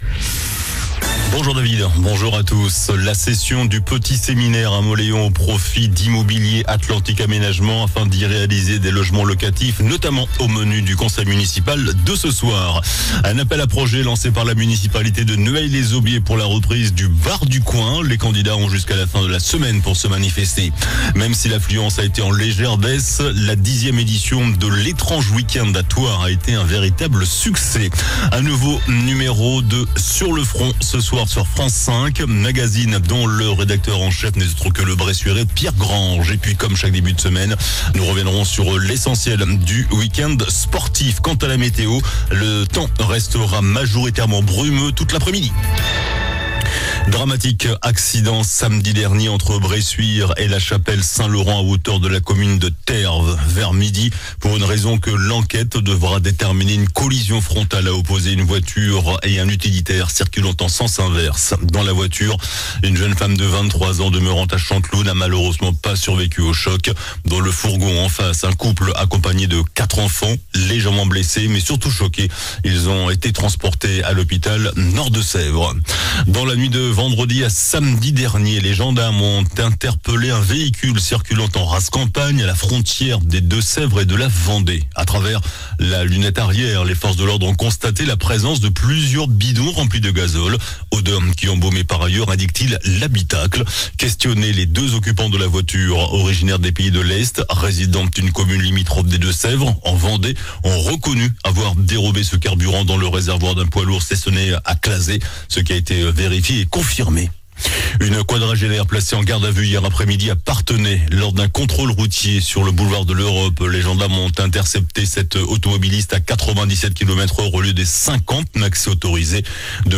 JOURNAL DU LUNDI 04 NOVEMBRE ( MIDI )